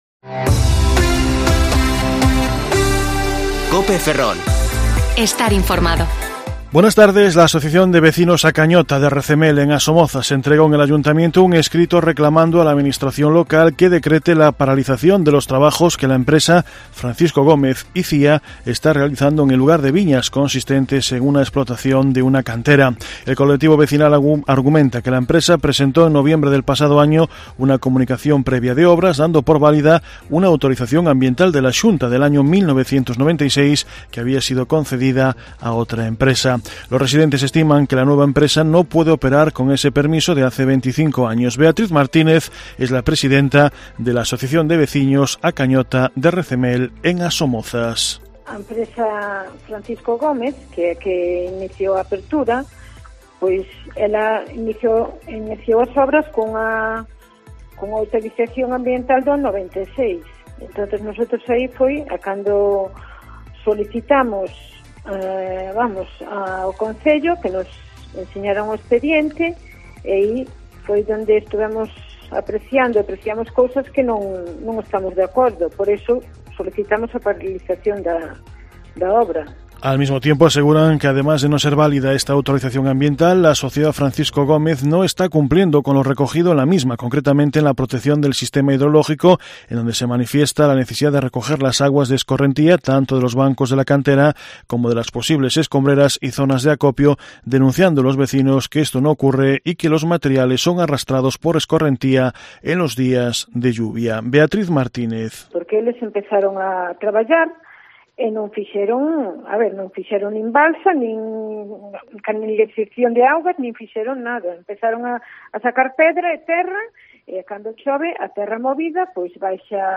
Informativo Mediodía COPE Ferrol 10/5/2021 (De 14,20 a 14,30 horas)